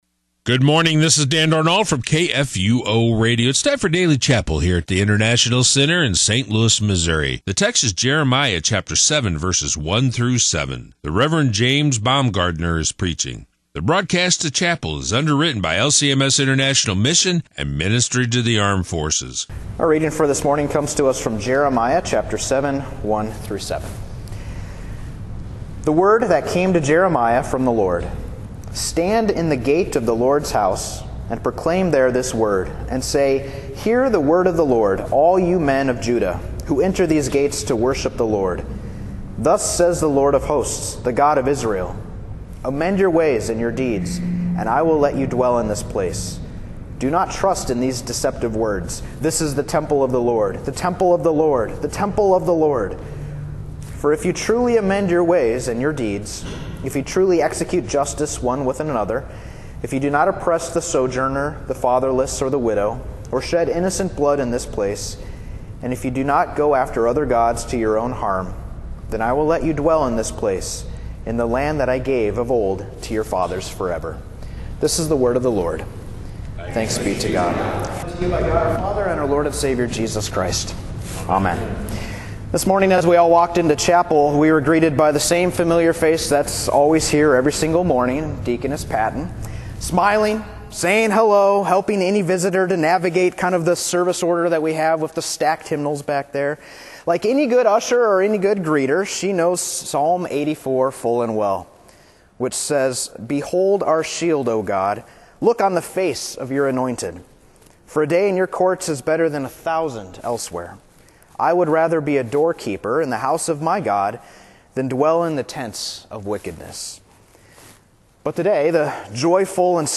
Daily Chapel